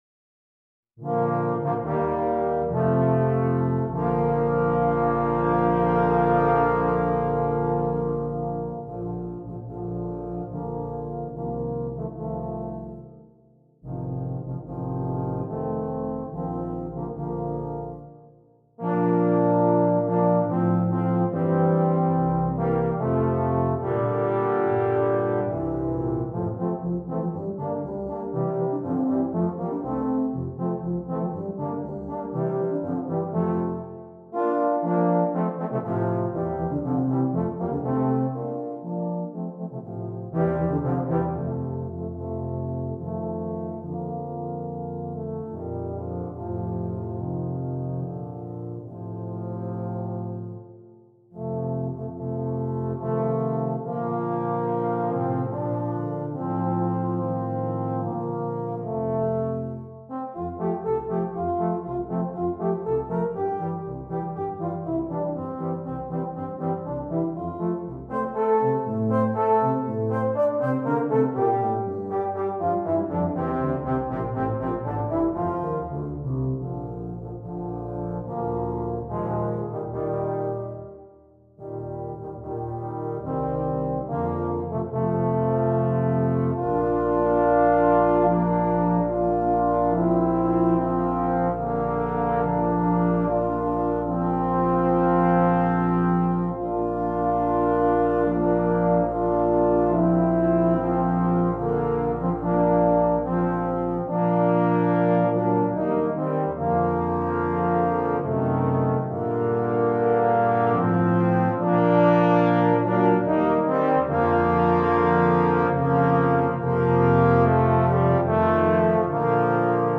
Besetzung: Tuba Quartet